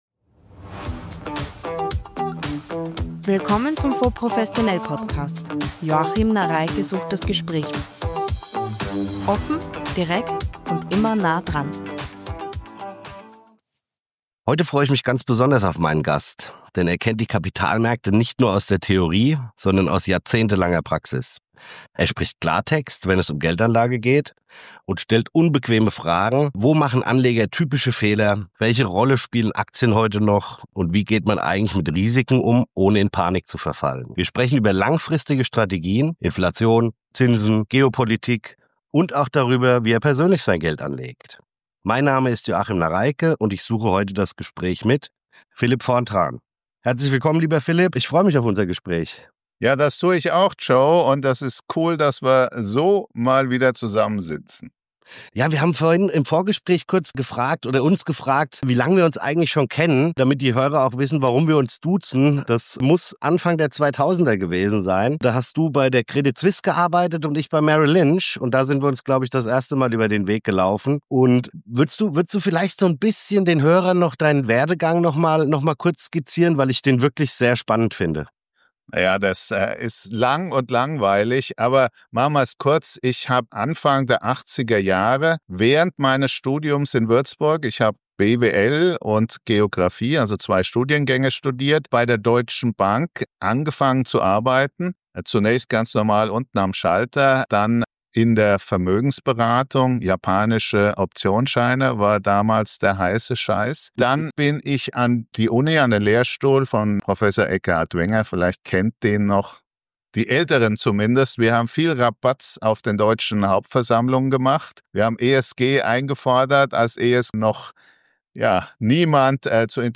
Ein Klartext-Gespräch über Strategien, Risiken und persönliche Erfahrungen.